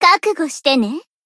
贡献 ） 分类:蔚蓝档案语音 协议:Copyright 您不可以覆盖此文件。
BA_V_Mutsuki_Battle_Buffself_1.ogg